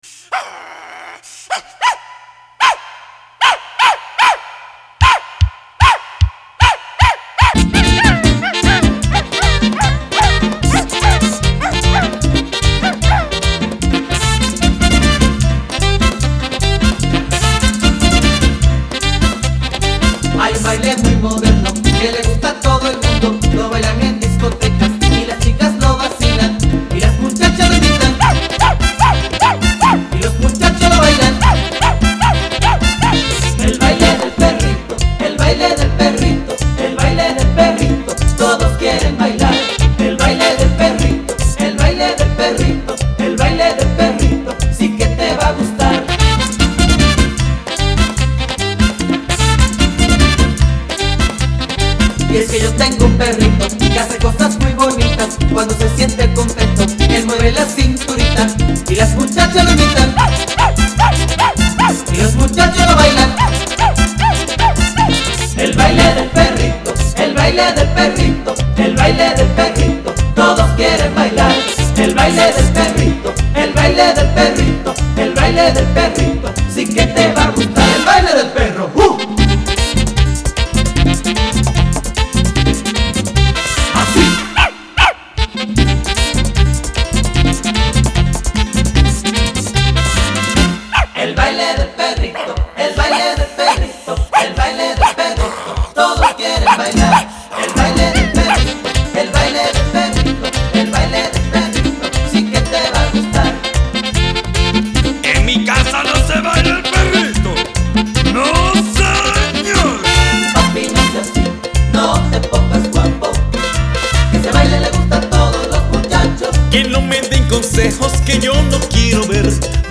Очень задорная и ритмичная мелодия!